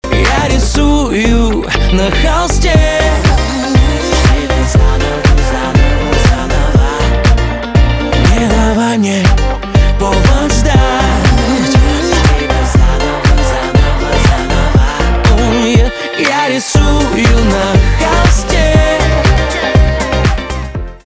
поп
гитара
басы